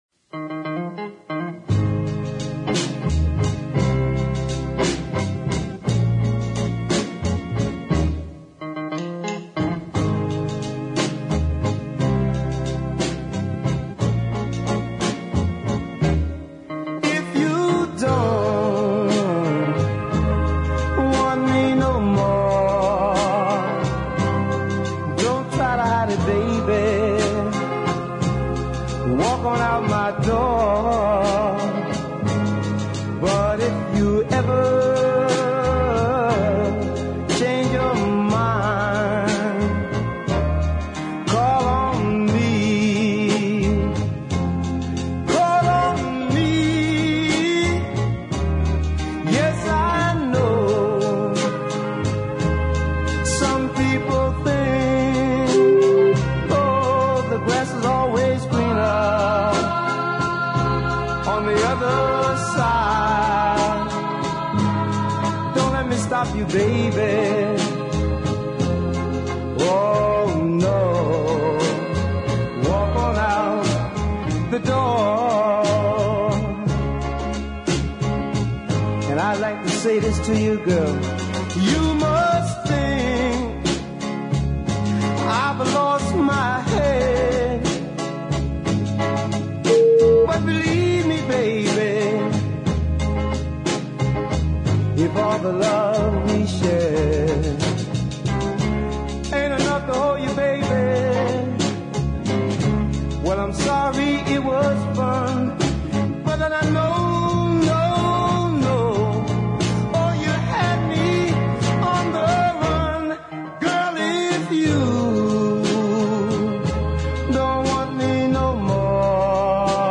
New York ballad